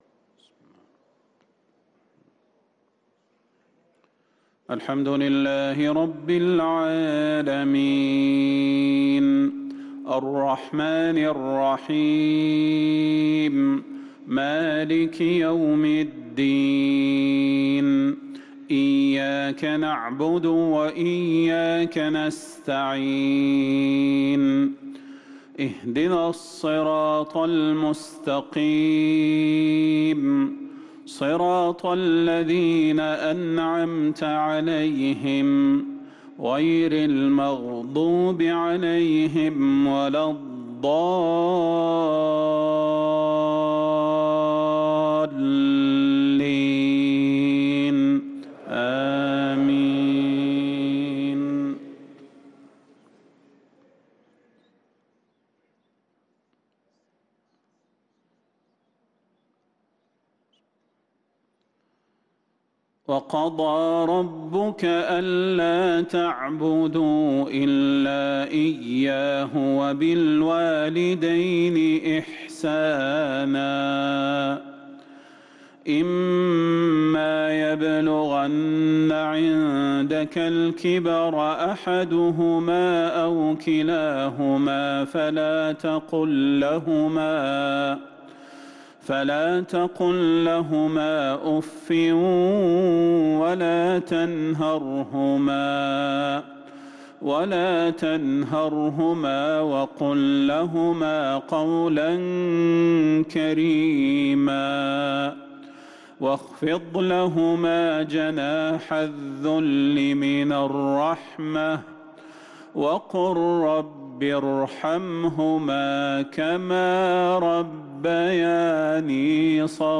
صلاة المغرب للقارئ صلاح البدير 6 ذو الحجة 1444 هـ